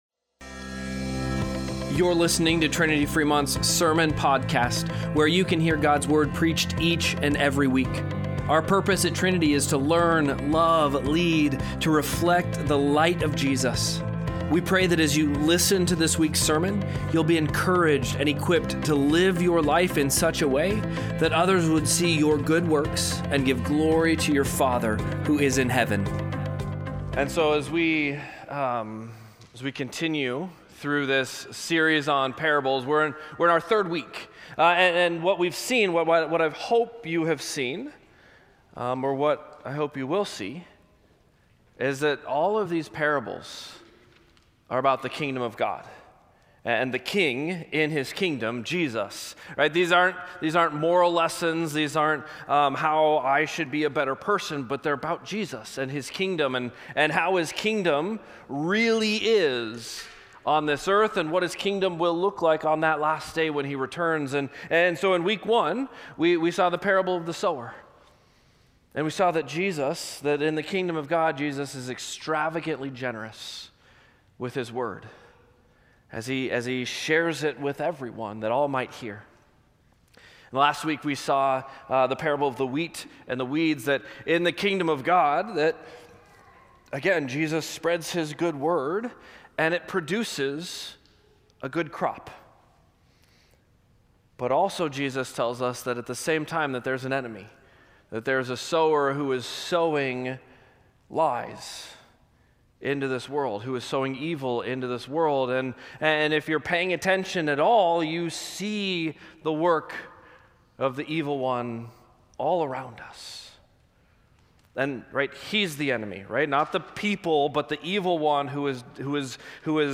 Sermon-Podcast-1-18.mp3